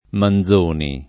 [ man z1 ni ]